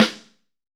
B.B SN 5.wav